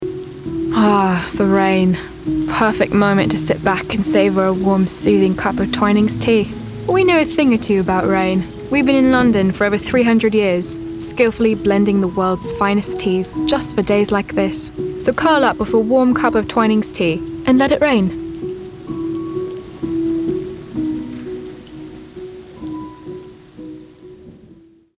Voix off
British Démo
18 - 35 ans - Mezzo-soprano